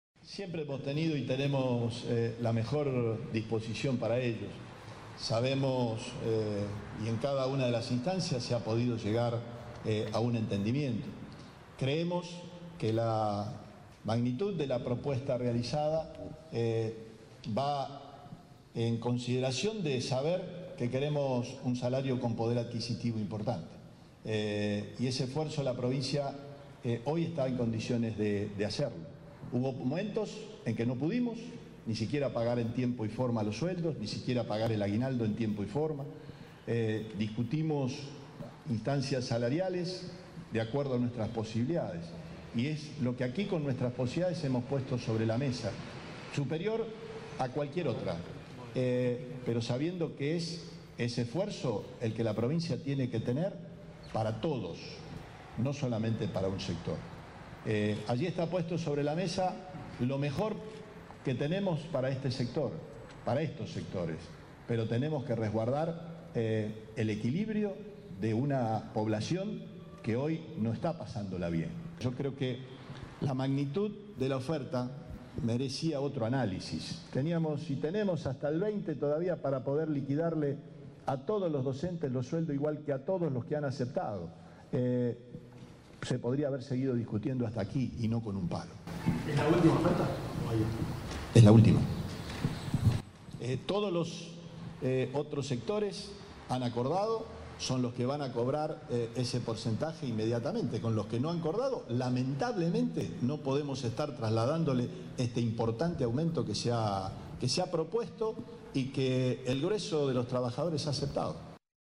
Este viernes, Omar Perotti les habló a los docentes de AMSAFE tras el rechazo de la oferta salarial y les comunicó que «es la última oferta».